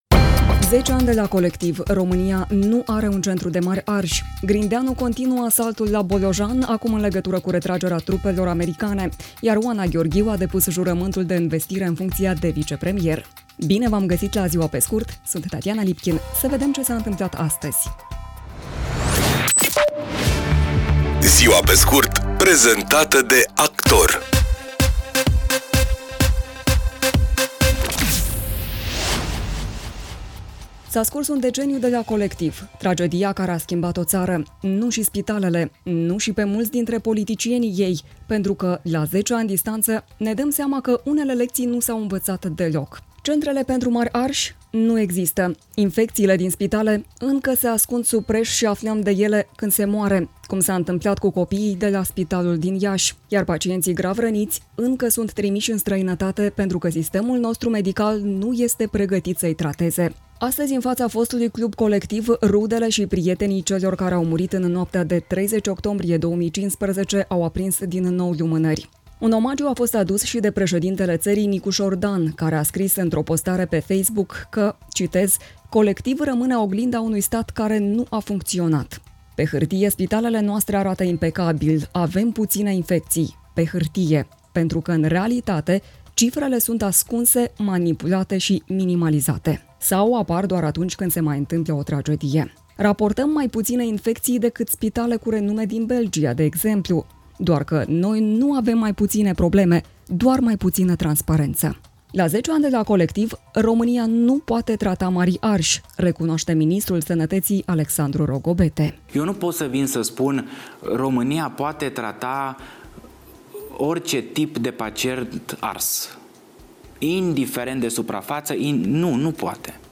„Ziua pe scurt” este un podcast zilnic de actualitate care oferă, în doar zece minute, o sinteză a principalelor cinci știri ale zilei. Formatul propune o abordare prietenoasă, echilibrată și relaxată a informației, adaptată publicului modern, aflat mereu în mișcare, dar care își dorește să rămână conectat la cele mai importante evenimente.